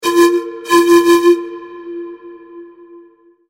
Poniżej zamieszczono przykładowe dźwięki otrzymane przy pomocy modelu quasi-fizycznego.
efekt tremolo